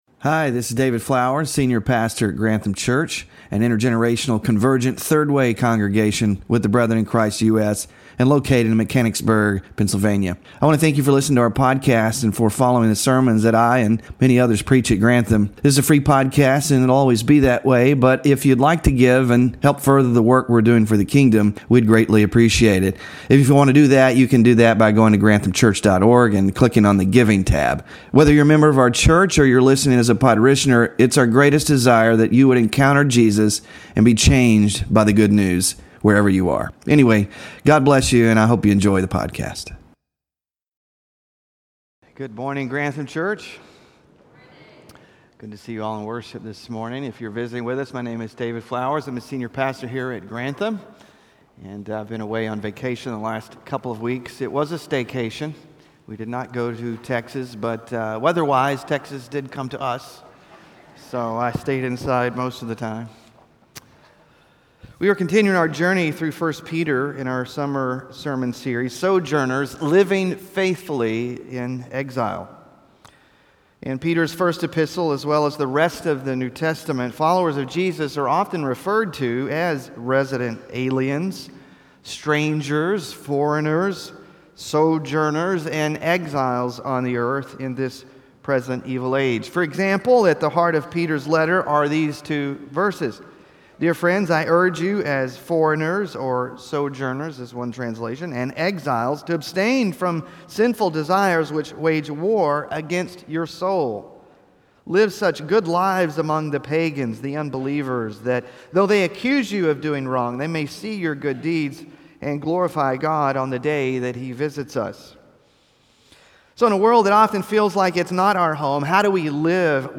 Sermon Focus